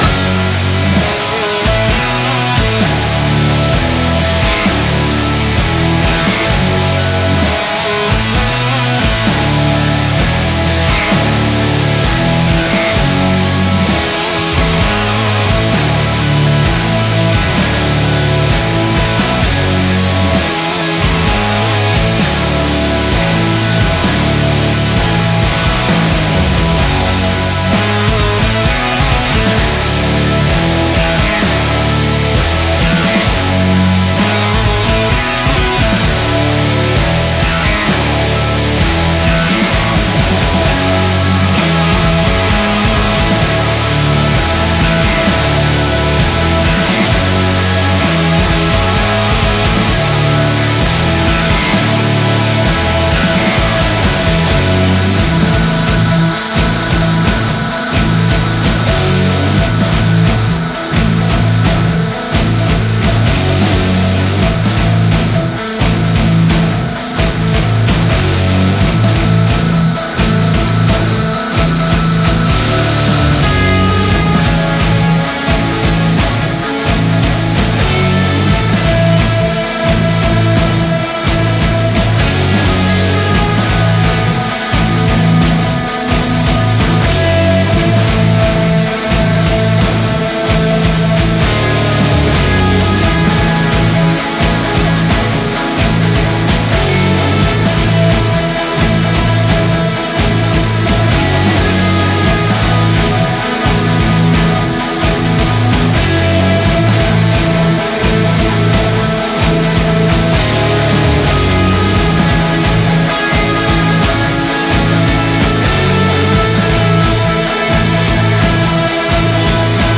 titres introspectifs